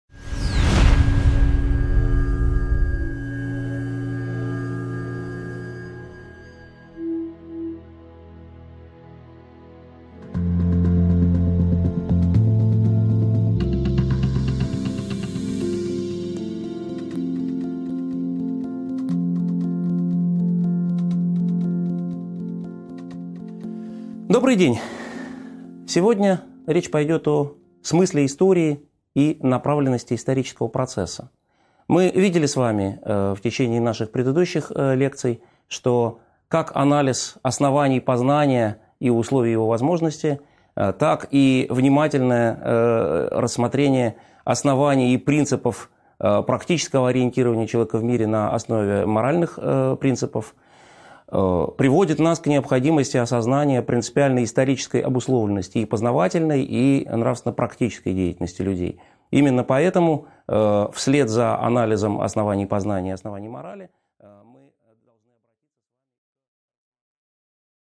Аудиокнига 13.1 Основные проблемы философии истории | Библиотека аудиокниг